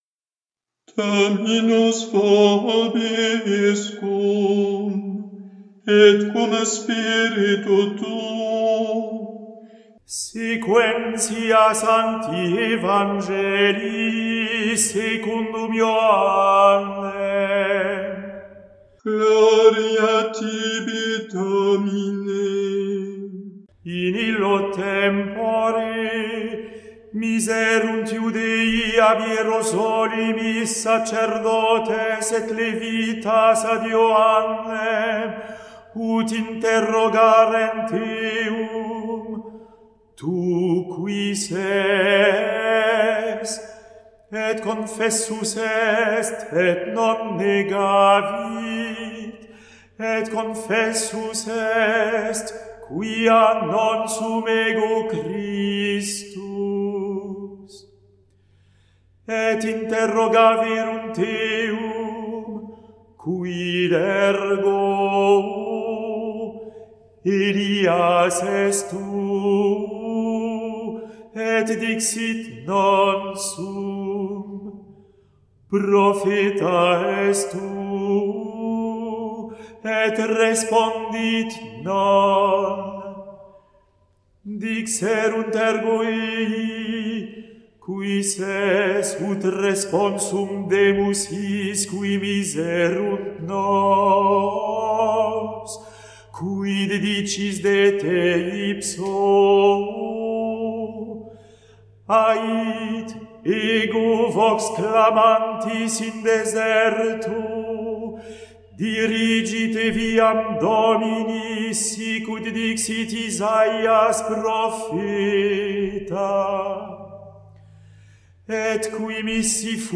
Evangelium